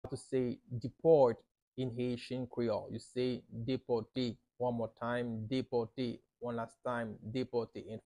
How to say “Deport” in Haitian Creole – “Depòte” pronunciation by a native Haitian tutor
“Depòte” Pronunciation in Haitian Creole by a native Haitian can be heard in the audio here or in the video below:
How-to-say-Deport-in-Haitian-Creole-–-Depote-pronunciation-by-a-native-Haitian-tutor.mp3